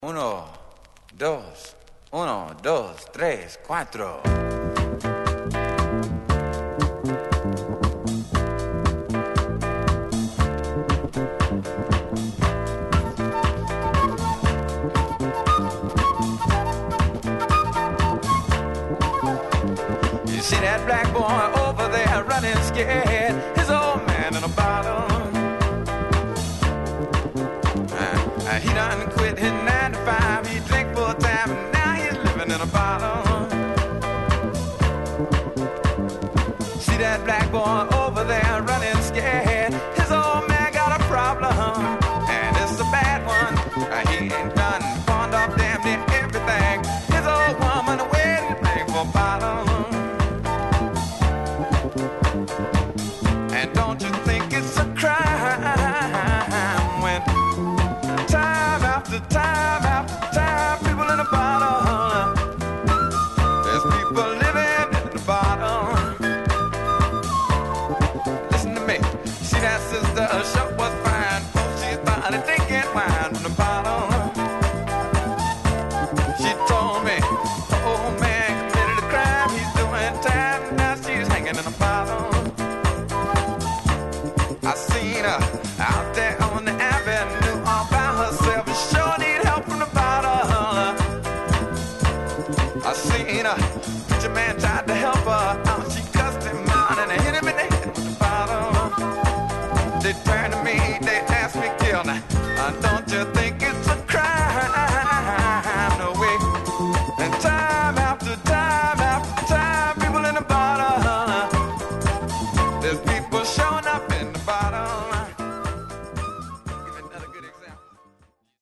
Rare Groove~Jazz Funk Classic!!
※細かい擦り傷があり無音部分、ドラムレスの部分でチリノイズが気になります。
※この盤からの録音ですので「試聴ファイル」にてご確認下さい。